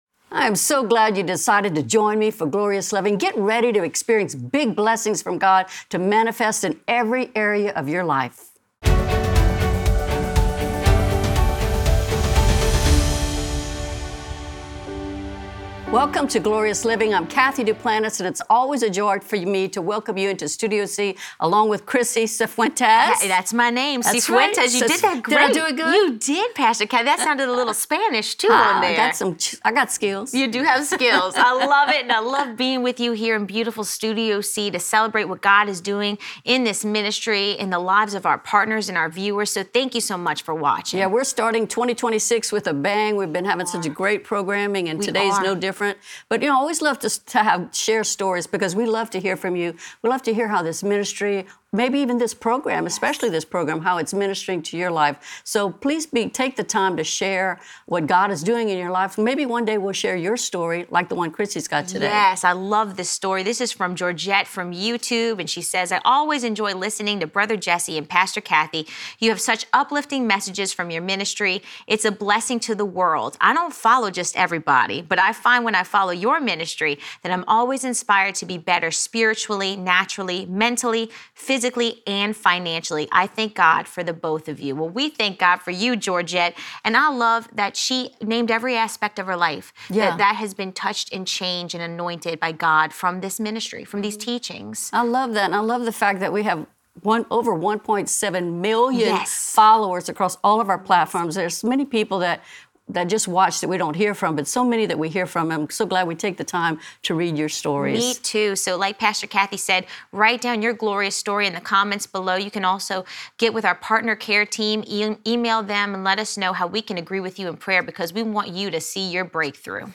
in Studio C!